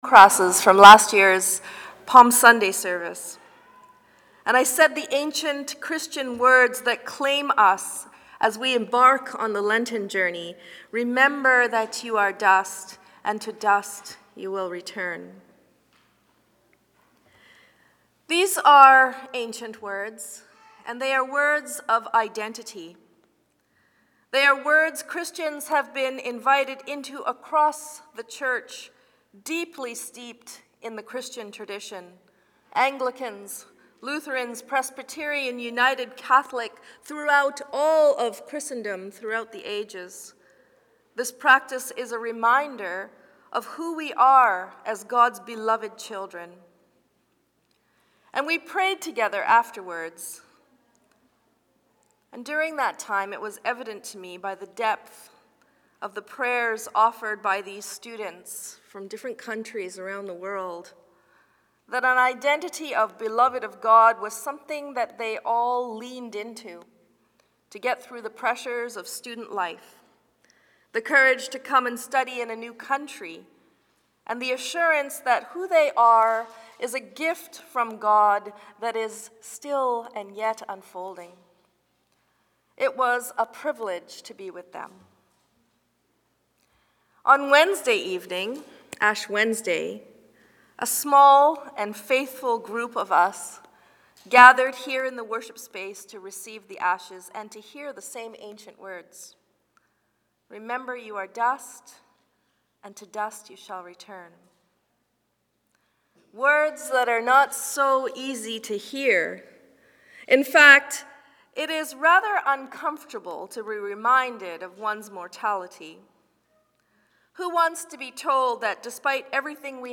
Sermons | St. Marys' Metchosin